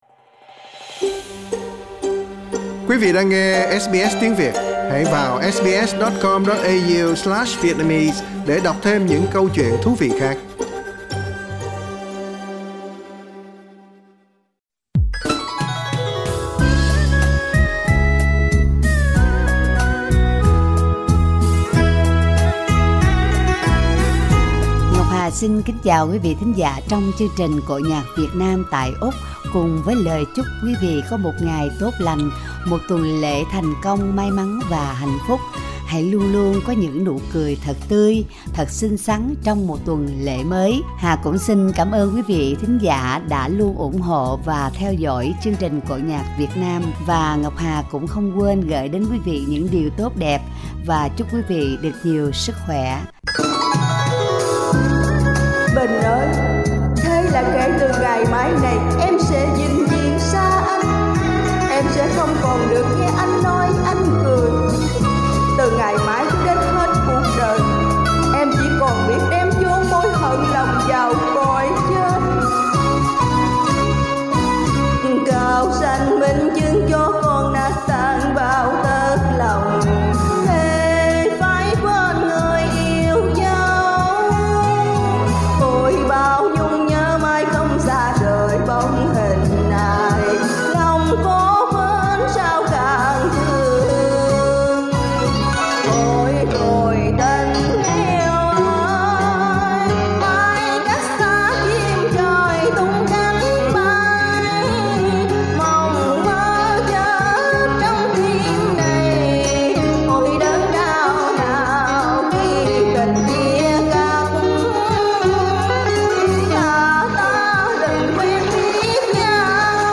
Giọng ca vọng cổ